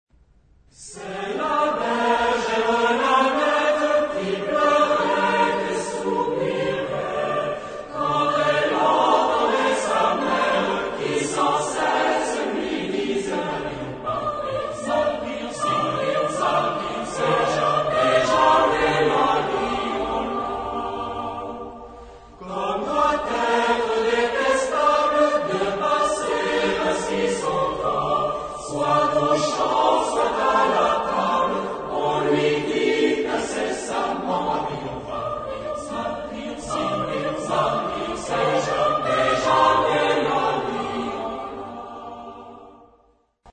Género/Estilo/Forma: Profano ; Popular
Carácter de la pieza : humorístico
Tipo de formación coral: SATB  (4 voces Coro mixto )
Tonalidad : sol menor